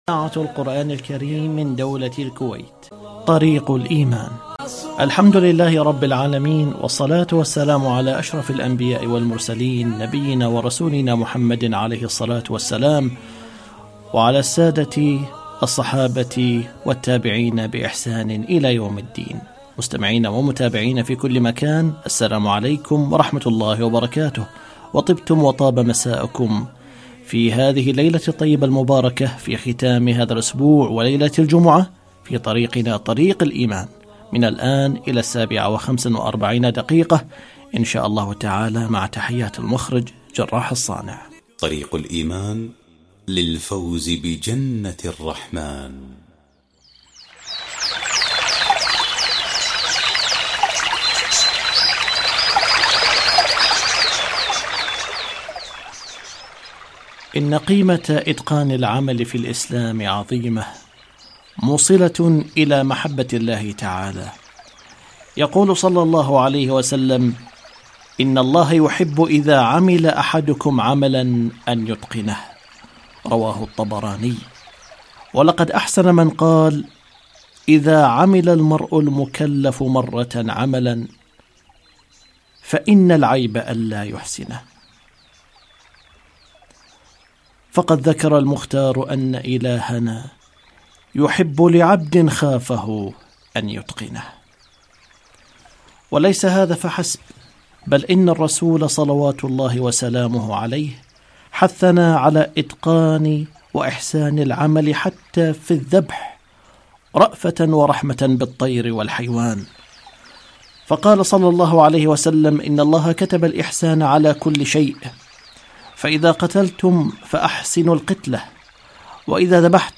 الشباب والفراغ - لقاء إذاعي